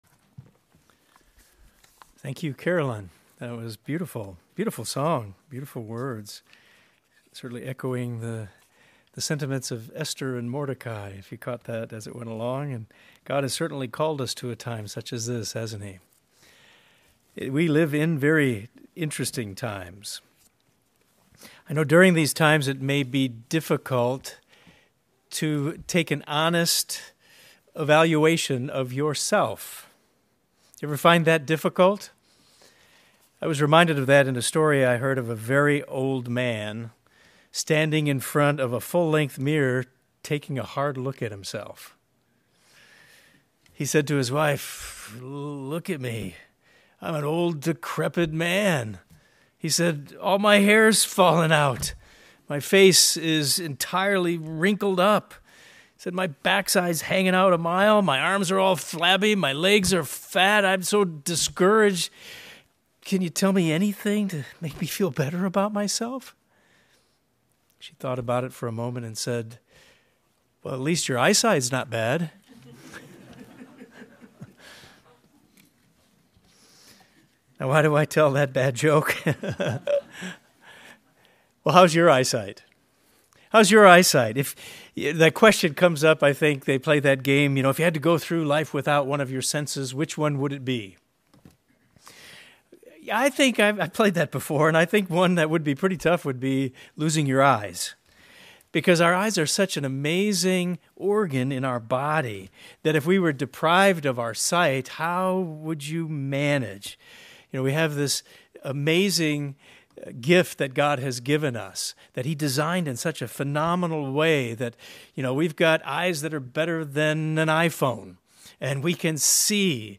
This sermon discussed our need to see through spiritual eyes.